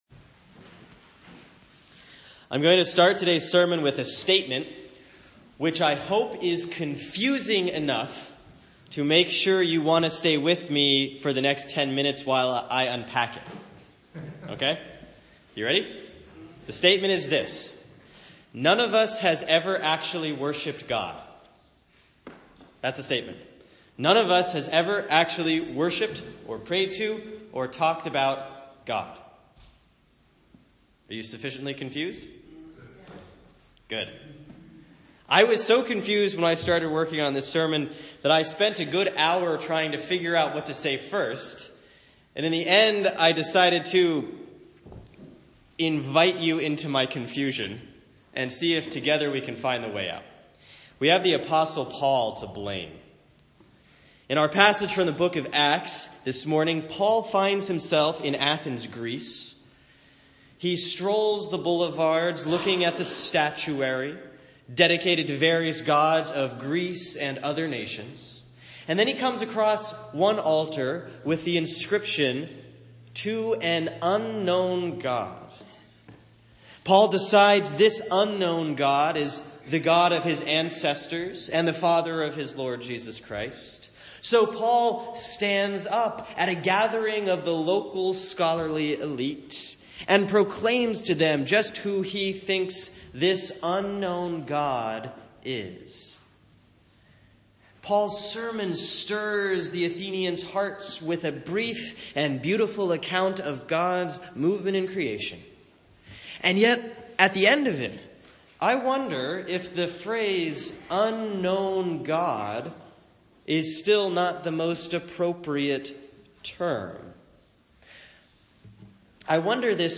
Sermon for Sunday, May 21, 2017 || Easter 6A || Acts 17:22-31